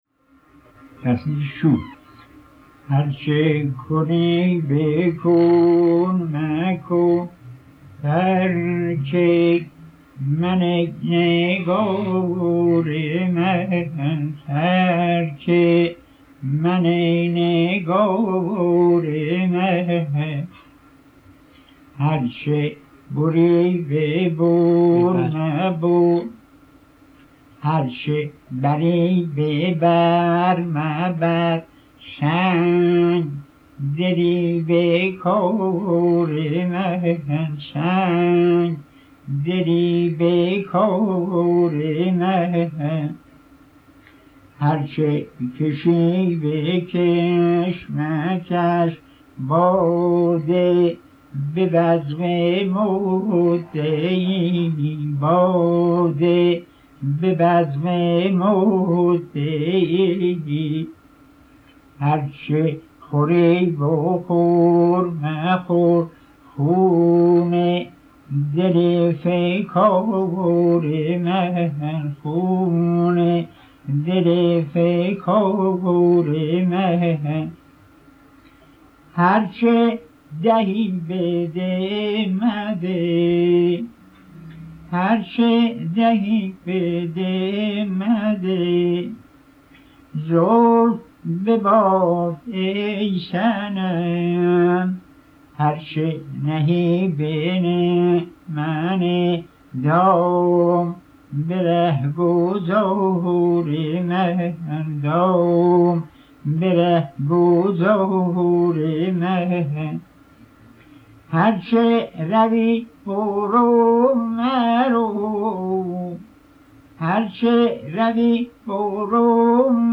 11-Tasnife-Shur.mp3